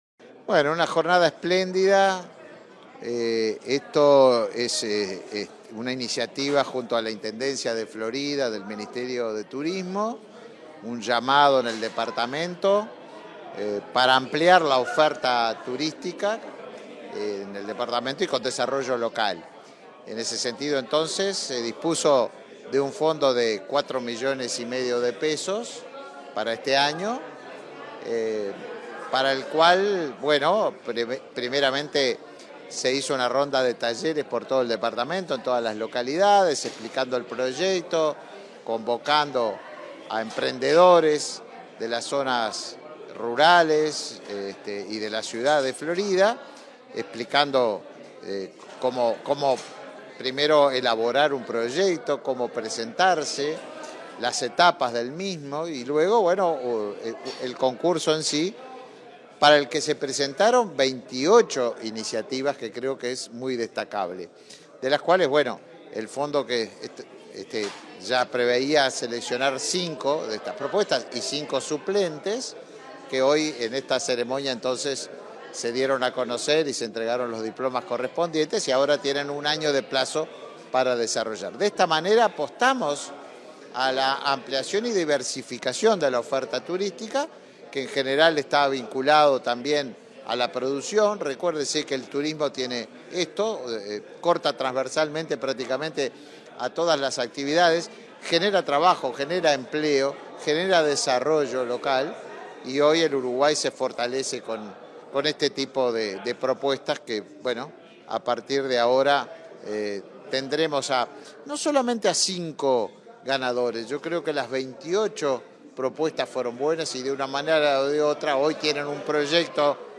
Entrevista al ministro de Turismo, Tabaré Viera
Entrevista al ministro de Turismo, Tabaré Viera 06/09/2022 Compartir Facebook X Copiar enlace WhatsApp LinkedIn Tras participar en la conferencia de prensa por la selección de proyectos de Fondos Concursables en Florida, este 5 de setiembre, el ministro Tabaré Viera dialogó con Comunicación Presidencial.